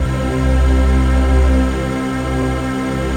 Index of /90_sSampleCDs/Optical Media International - Sonic Images Library/SI1_Breath Choir/SI1_BreathMellow